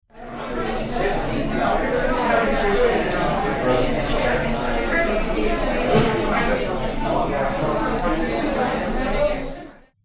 Restaurant Chatter 03
Restaurant_chatter_03.mp3